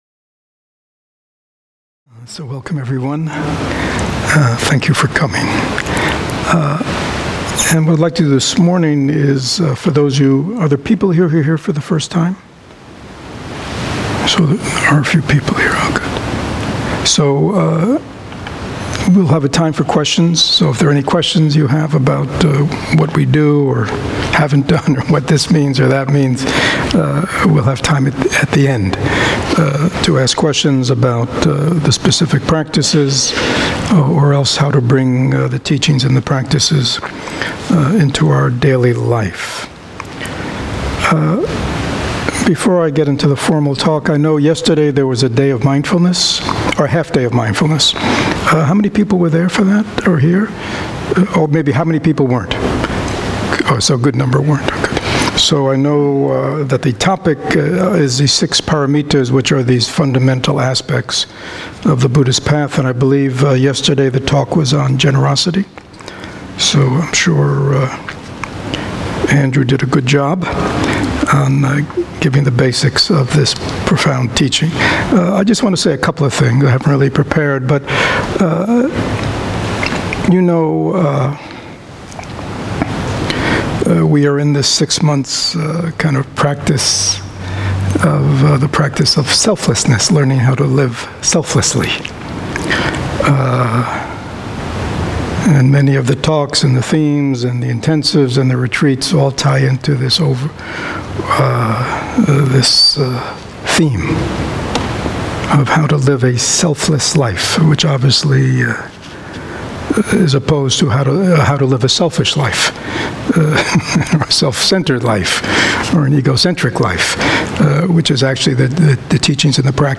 The True Meaning of Generosity Sangha Talk